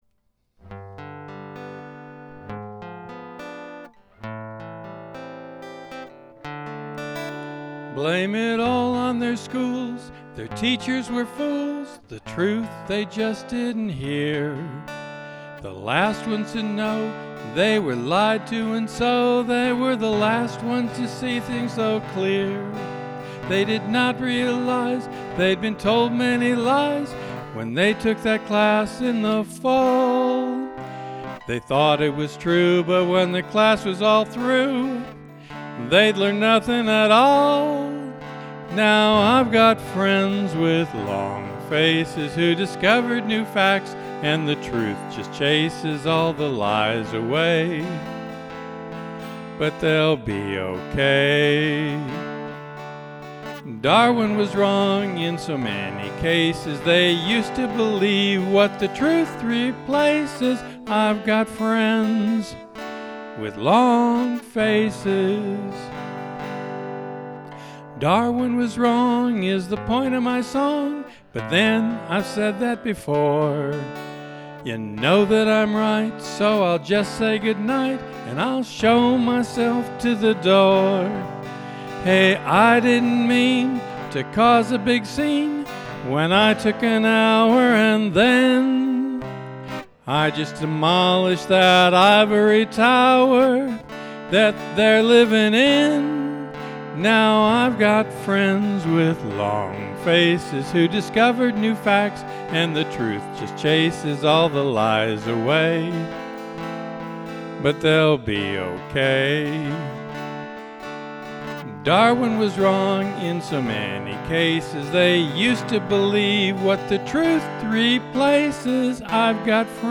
Song Parody - April 2020